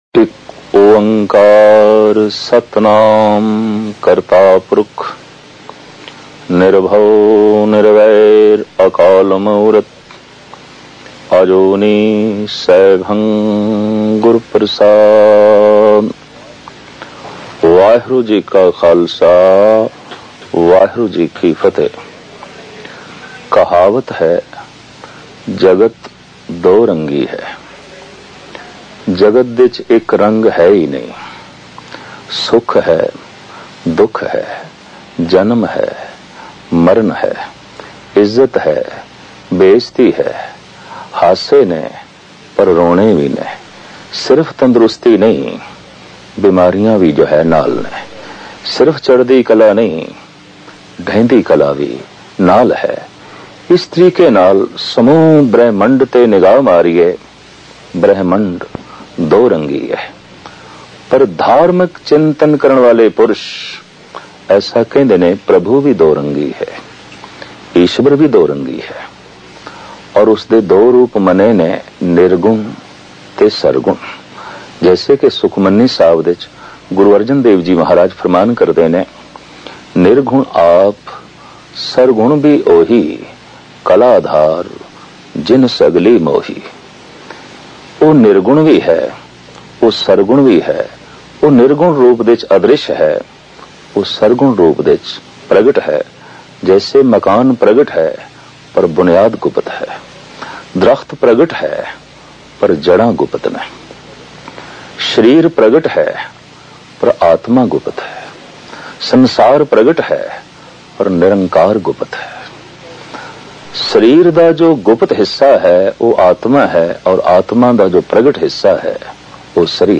Listen and Download Katha